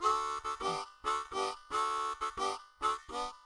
口琴练习剪辑 " 口琴节奏 08 ( 可循环 )
描述：这是一个演奏节奏裂缝的海洋乐队口琴的录音。
Tag: 口琴 节奏 重点 G